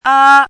怎么读
ā ē